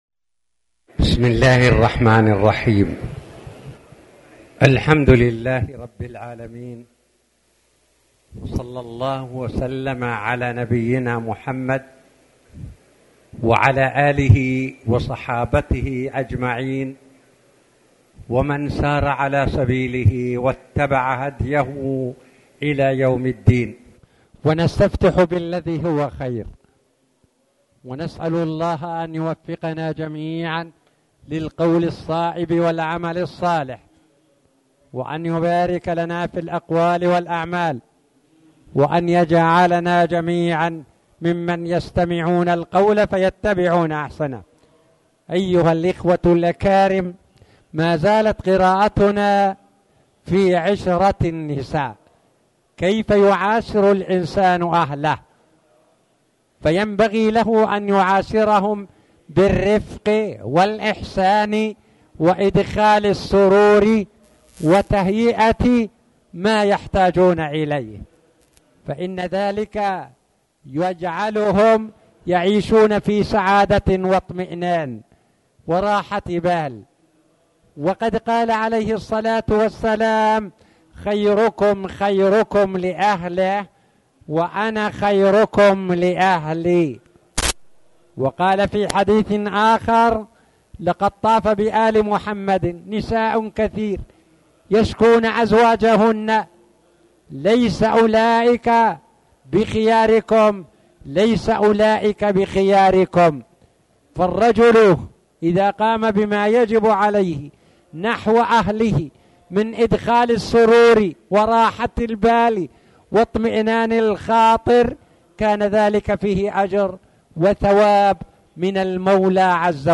تاريخ النشر ٢٤ جمادى الأولى ١٤٣٨ هـ المكان: المسجد الحرام الشيخ